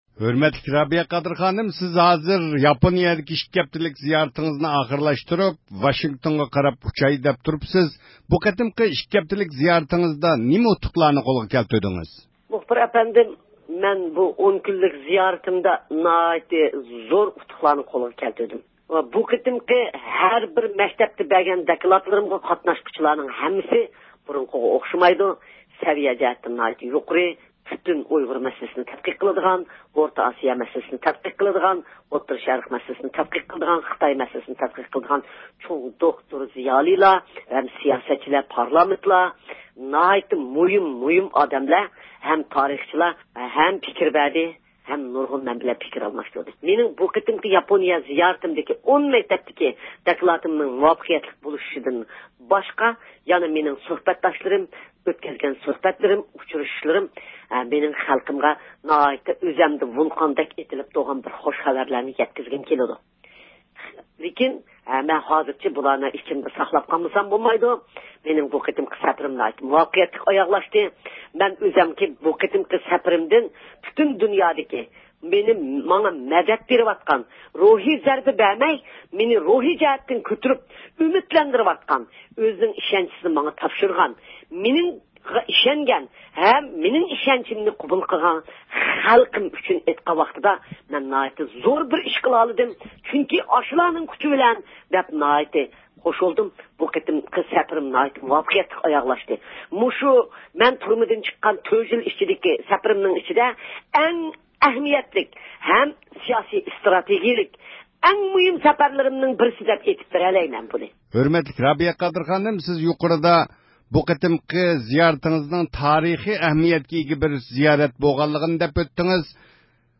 بىز ئۇ ئايرۇپىلانغا چىقىشتىن بۇرۇن ئۇنىڭغا تېلېفون قىلىپ ئىككى ھەپتىلىك زىيارىتىنىڭ قانداق ئۆتكەنلىكىنى سورىدۇق. ئۇ ياپونىيە زىيارىتىنىڭ ئۇيغۇرلار ئۈچۈن تارىخىي زىيارەت بولغانلىقىنى ئېيتتى.